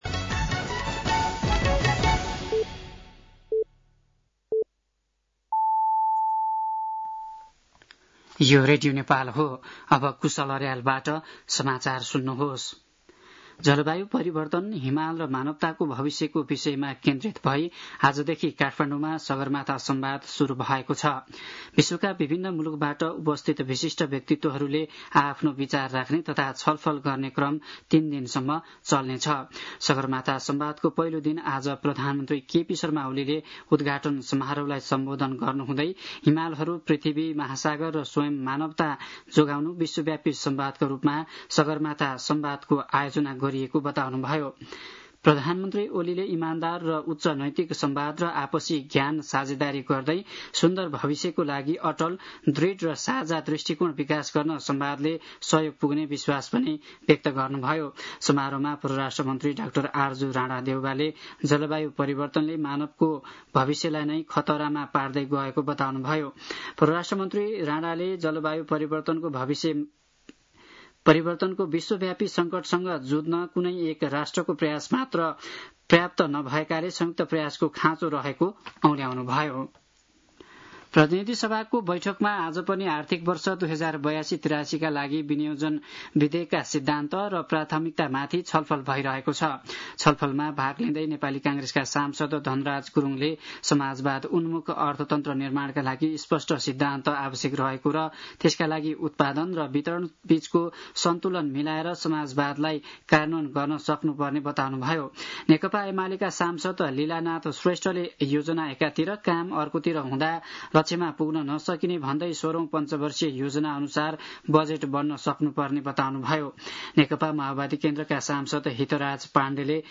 साँझ ५ बजेको नेपाली समाचार : २ जेठ , २०८२
5-pm-nepali-news-02-2.mp3